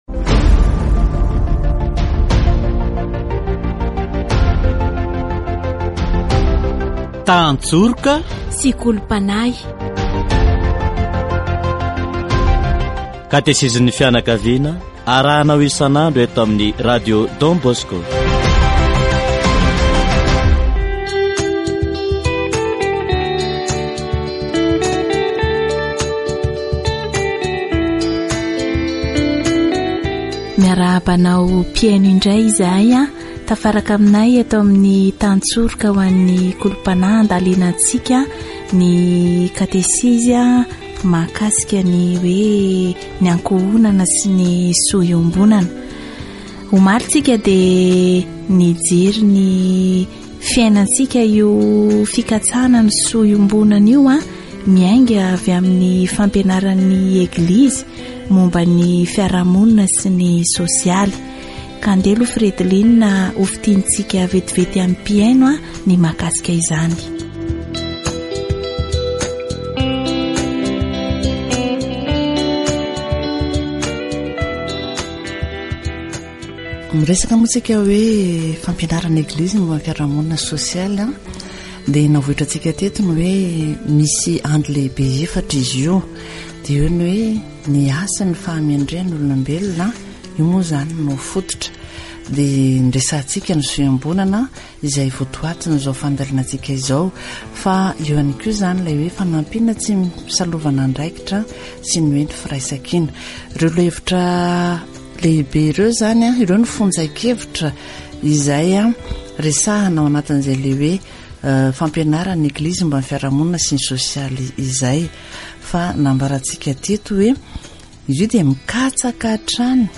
Ny soa iombonana dia tsy misy lanjany raha tsy miompana amin'ny voaary manontolo. Katesizy momba ny ankohonana